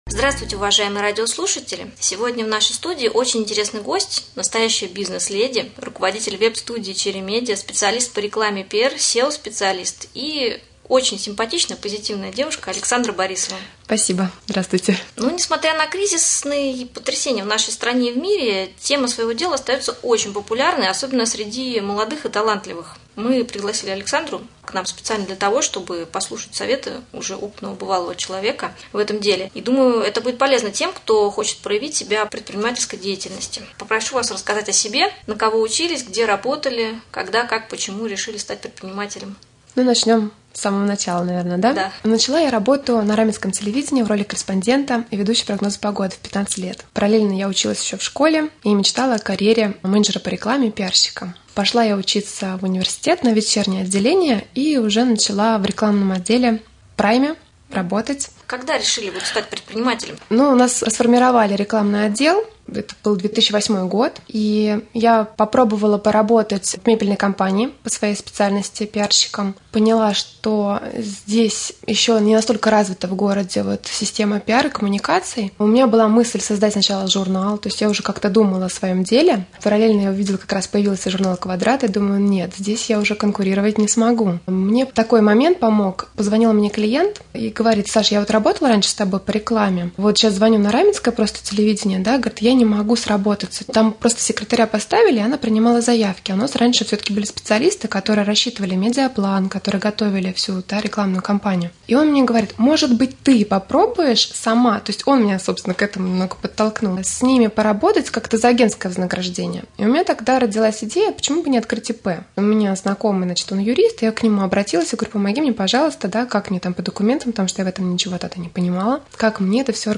В гостях у Раменского радио